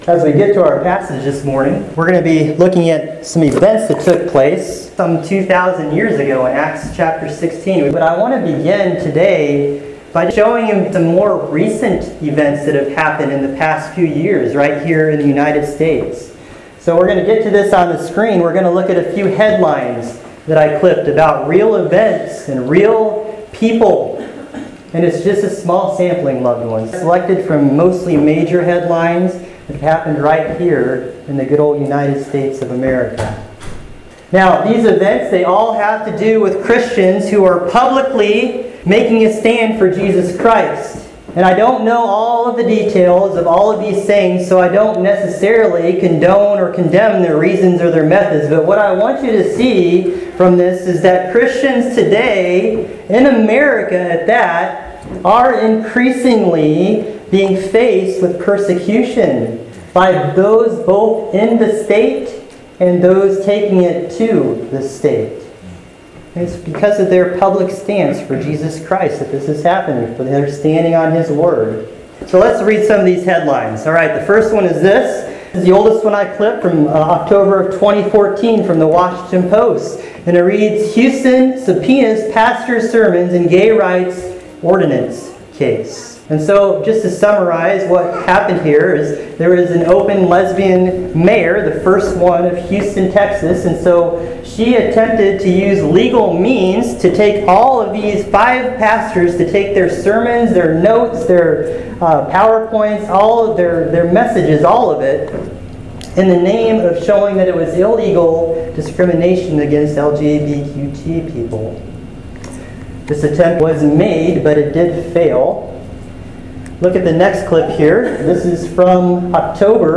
Acts 16:35-40: When Faith and State Collide – First Baptist Church of Clear Lake, SD